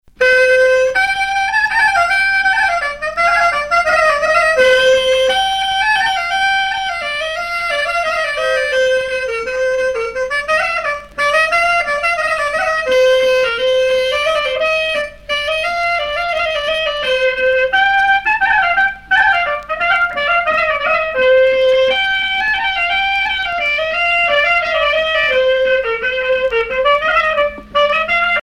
Rostrenen
danse : mazurka
Sonneurs de clarinette